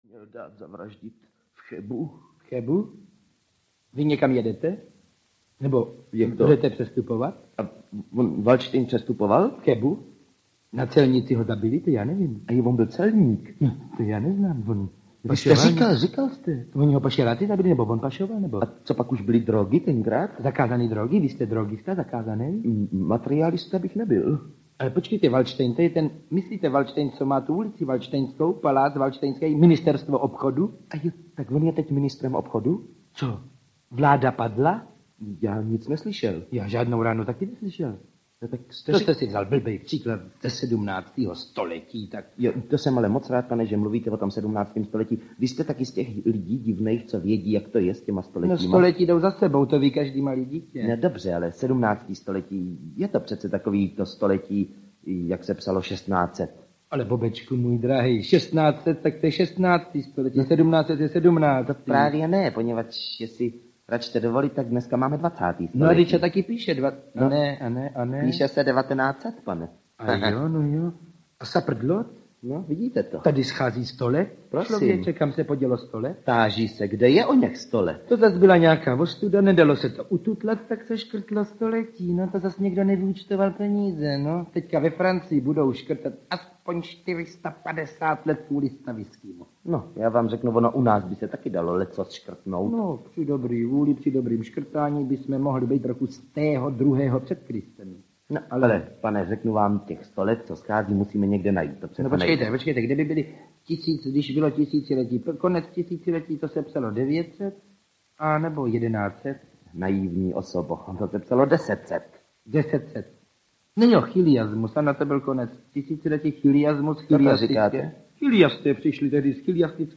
Suma sumárum audiokniha